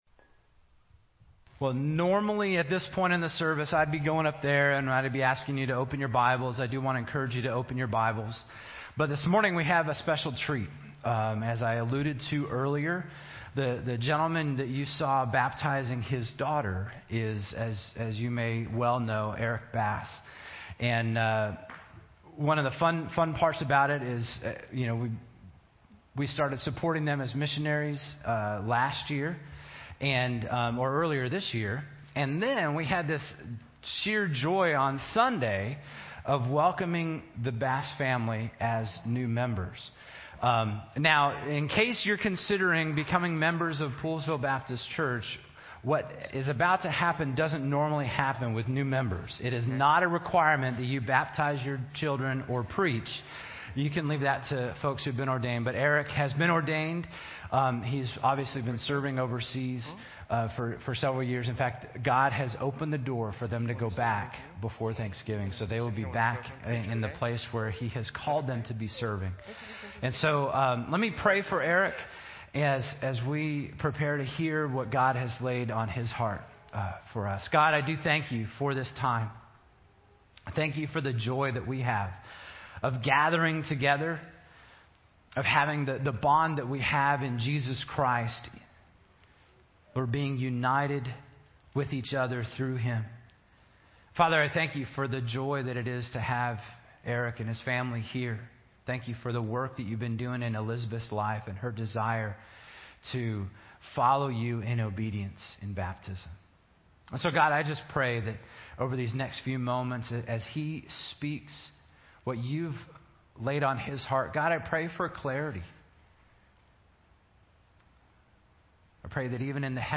Individual Sermons